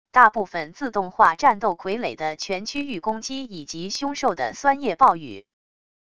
大部分自动化战斗傀儡的全区域攻击以及凶兽的酸液暴雨wav音频